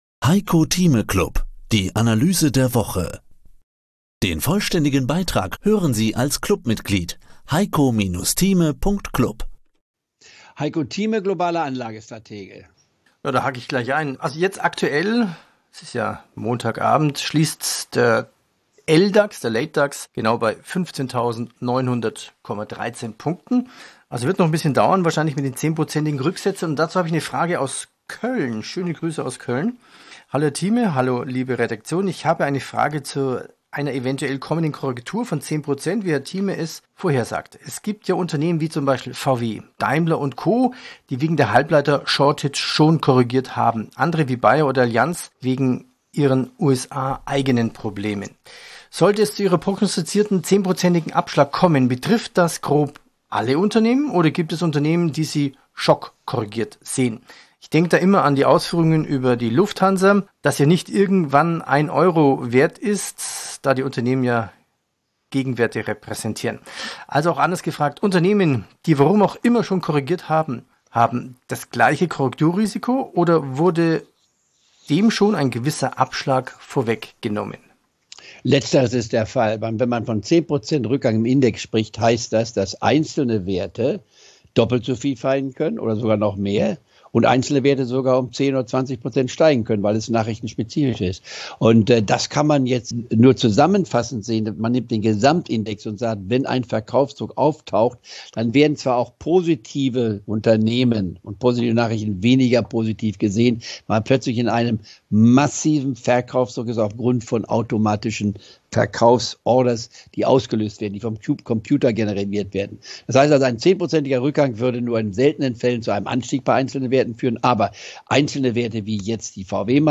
Im Heiko Thieme Club hören Sie ein mal wöchentlich ein exklusives Interview zum aktuellen Börsengeschehen, Einschätzung der Marktlage, Erklärungen wie die Börse funktioniert oder Analysen zu einzelnen Aktienwerten.